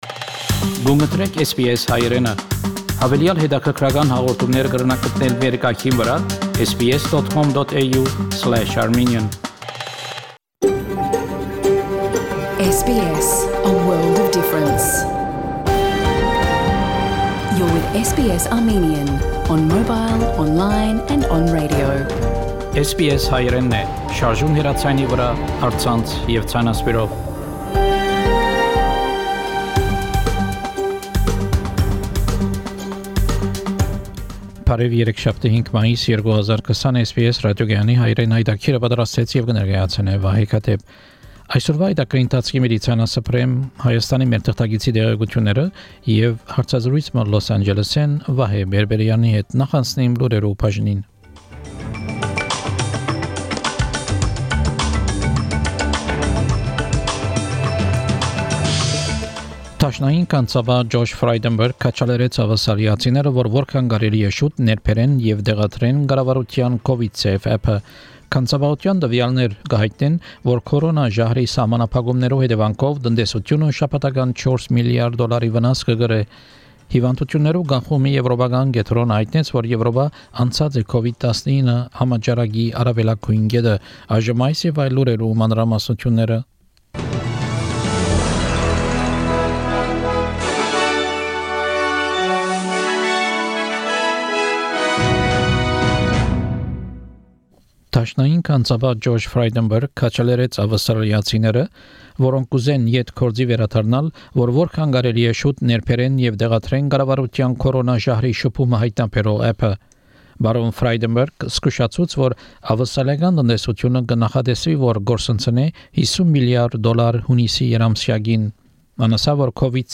SBS Armenian news bulletin – 5 May 2020